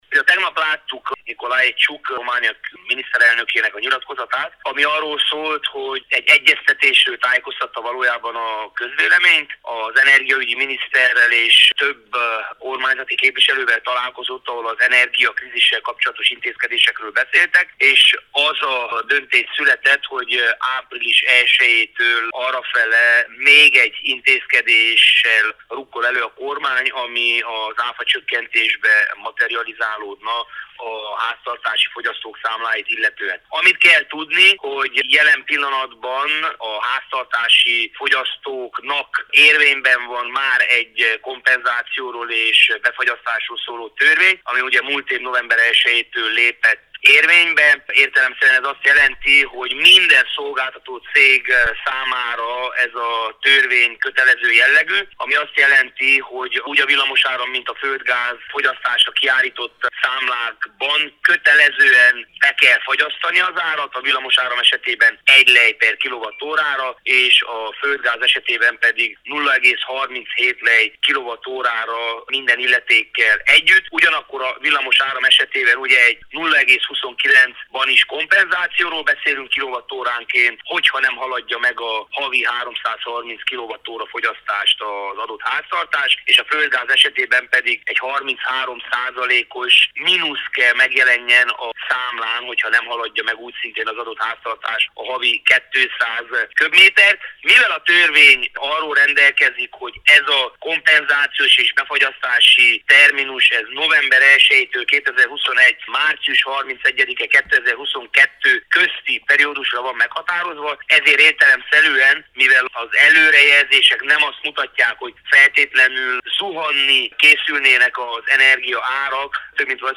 Antal Lóránt szenátort, az Energiaügyi Bizottság elnökét kérdezte